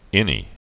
(ĭnē)